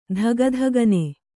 ♪ dhagadhagane